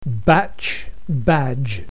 batchbadge.mp3